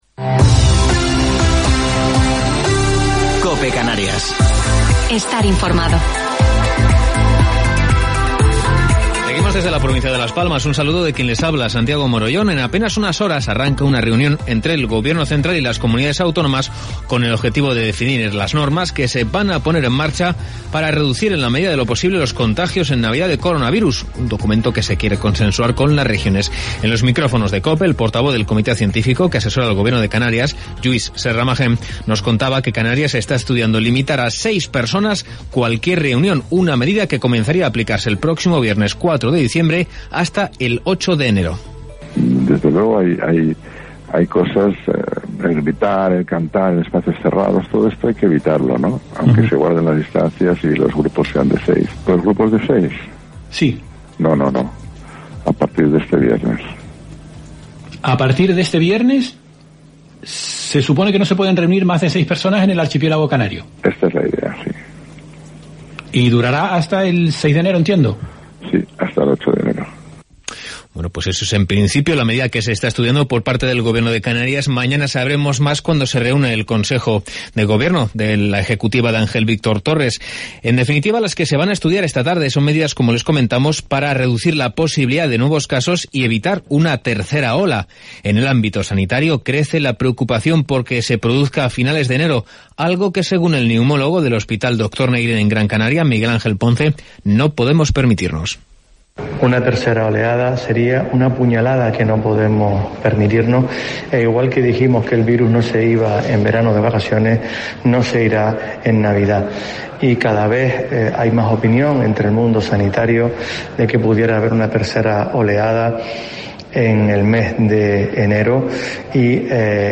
Informativo local 2 de Diciembre del 2020